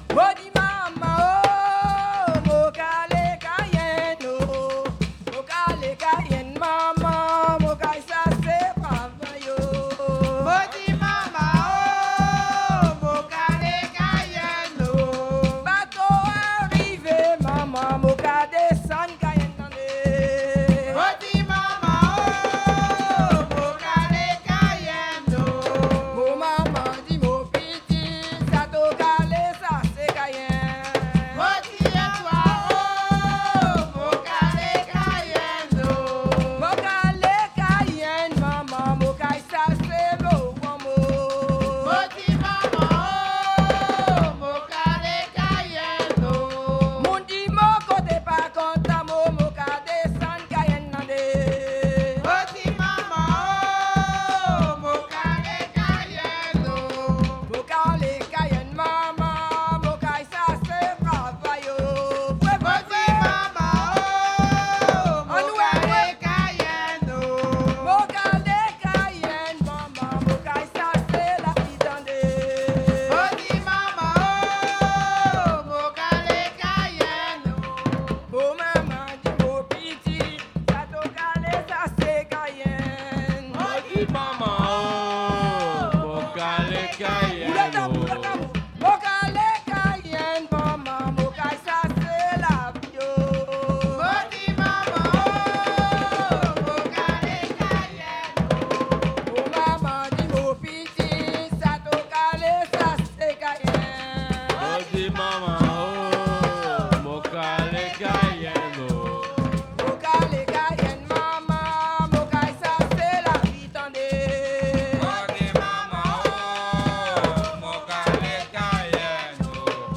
Soirée Mémorial
danse : kasékò (créole)
Pièce musicale inédite